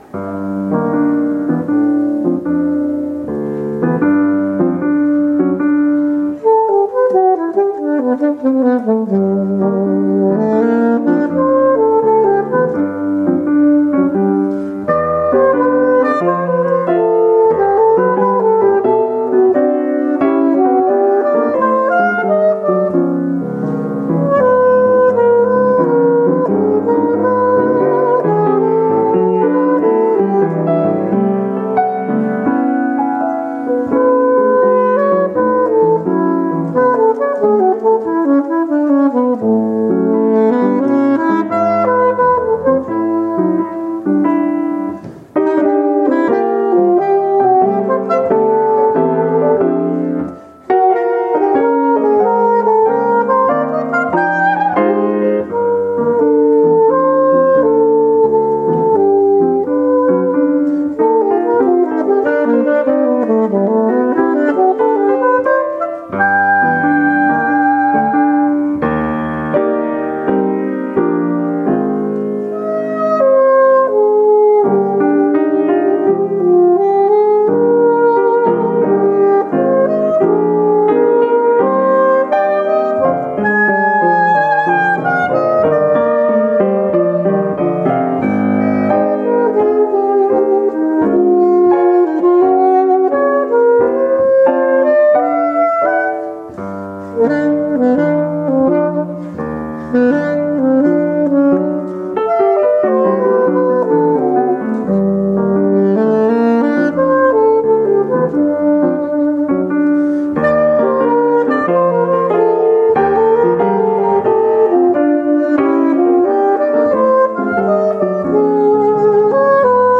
As a bit of a warning, most of the music was performed with very little rehearsal (disclaimer to cover any and all wrong notes, etc.), and all of the recordings were made with the built-in microphone on my iPod (covers balance issues and occasional sags in fidelity).
The latter location worked better, but still needed a bit of equalization to get rid of a bit of excessive bottom end. I added a bit of compression to all of the recordings to help even things out as well.
Danse pour saxophone alto en mi et piano by Darius Milhaud (1892–1974)
saxophone
piano